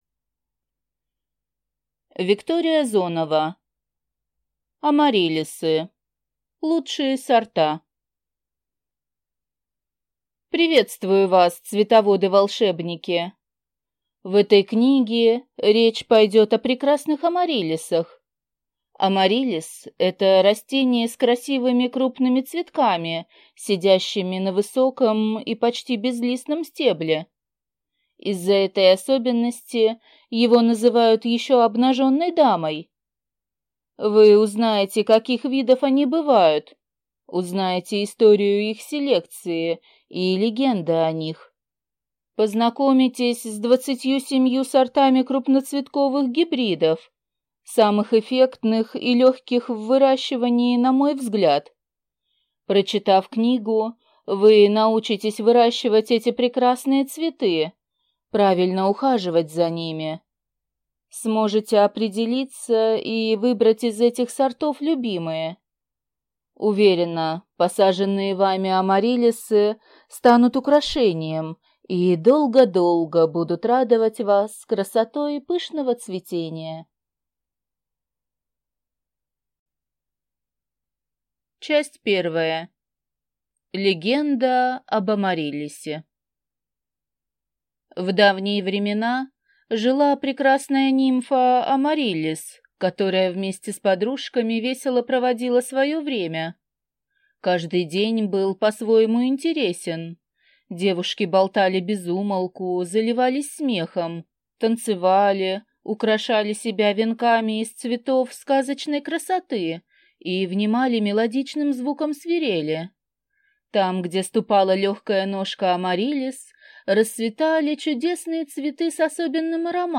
Аудиокнига Амариллисы. Лучшие сорта | Библиотека аудиокниг